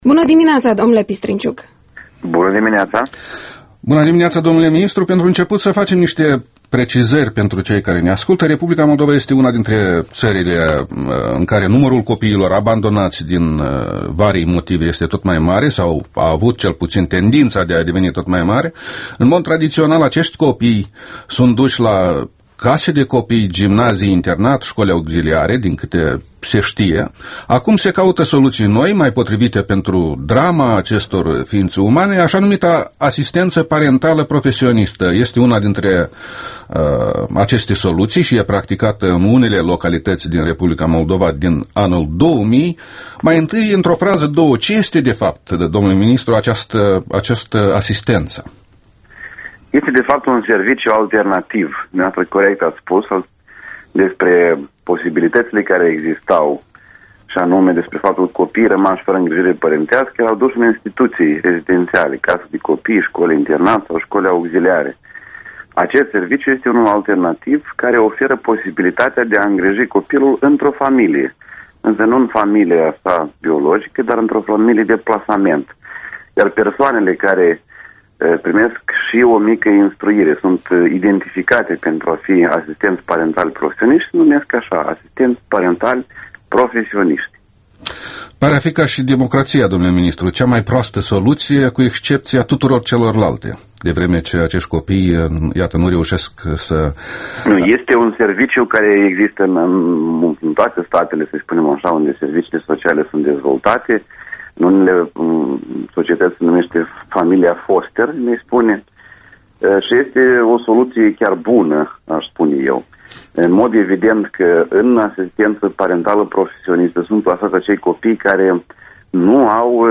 Interviul matinal EL: cu Vadim Pistrinciuc despre asistenţa parentală profesionistă